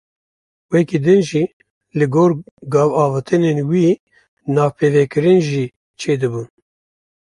Pronunciat com a (IPA)
/ɡoːr/